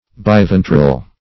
Search Result for " biventral" : The Collaborative International Dictionary of English v.0.48: Biventral \Bi*ven"tral\, a. [Pref. bi- + ventral.]